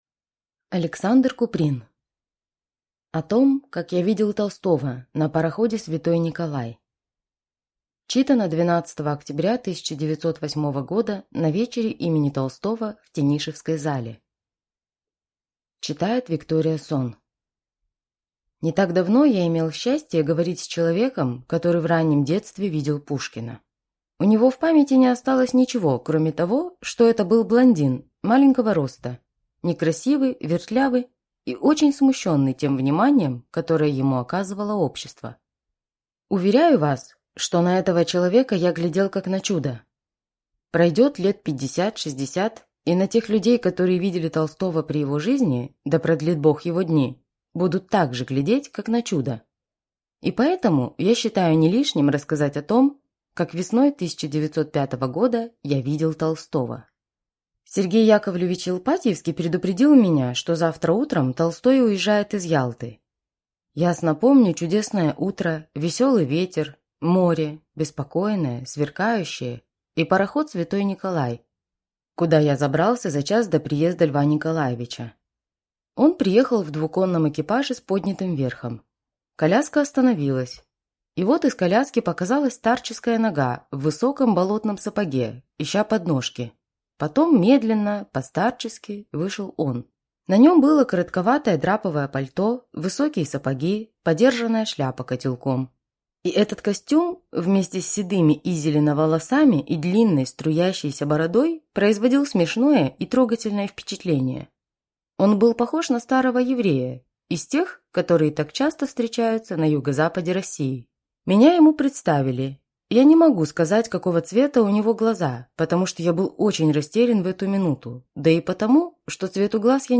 Аудиокнига О том, как я видел Толстого на пароходе «Св. Николай» | Библиотека аудиокниг